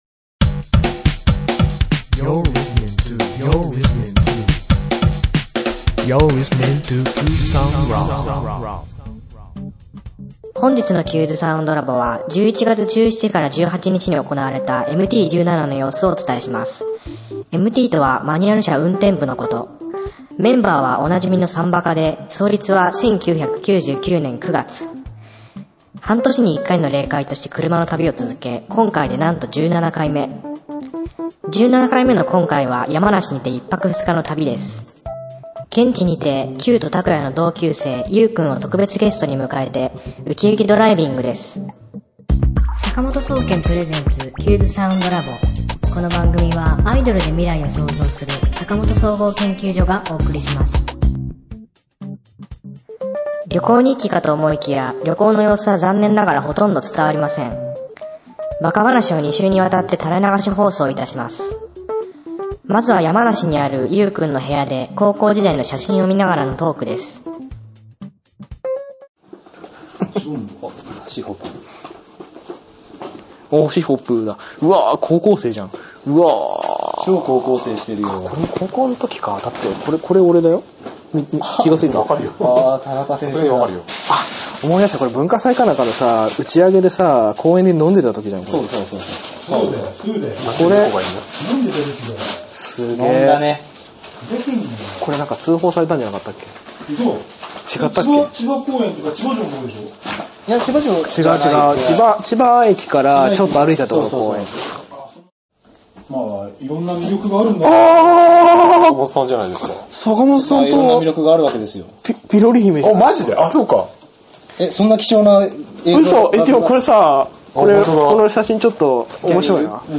と思いきや、旅の様子ではなく、単なるバカ話の垂れ流し放送です。